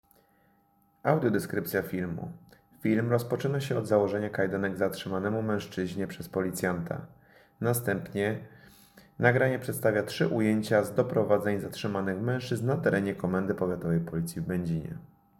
Nagranie audio Audiodeskrypcja.mp3